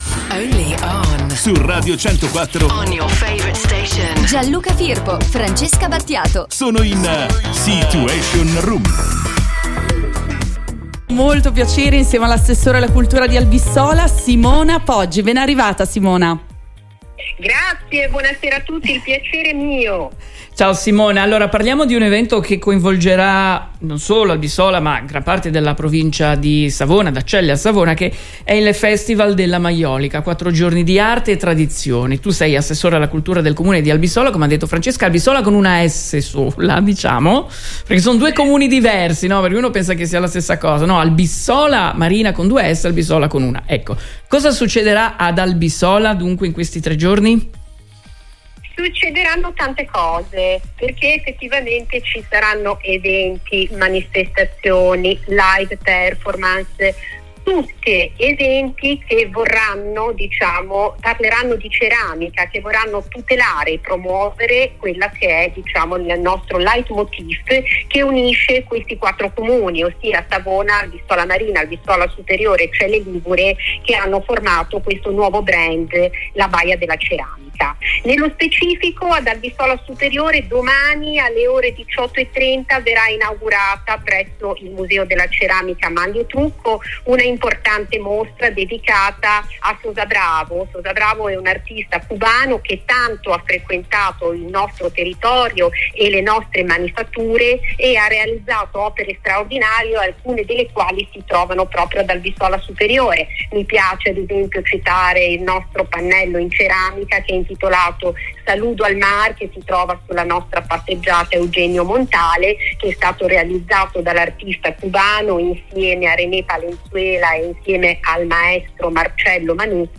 Simona Poggi assessore alla cultura e lo spettacolo di Albisola ci parla del festival della maiolica, quattro giorni di arte e tradizione